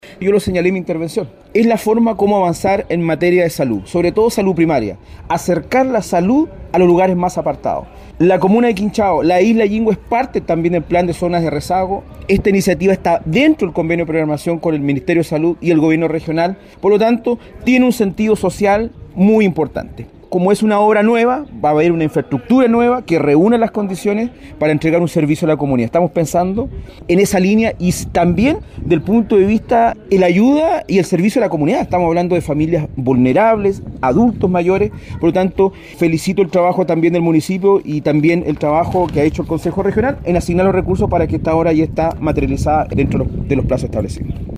Palabras similares tuvo el Consejero Regional, Francisco Cárcamo, destacando que dicho proyecto vendrá reforzar todo el trabajo que se realiza dentro del sistema de atención primaria de salud, especialmente en lugares apartados del archipiélago de Chiloé: